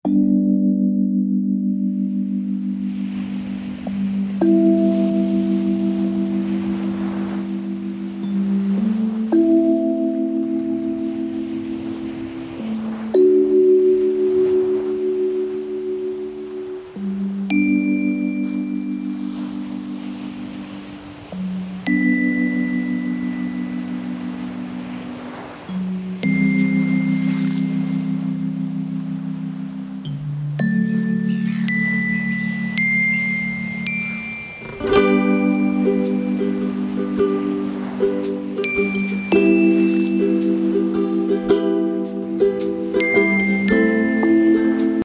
(multi-sensory instrumental)